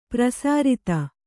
♪ prasārita